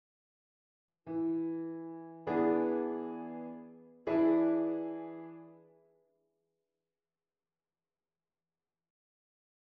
V7 in de vierstemmige zetting
volledige en onvolledige V7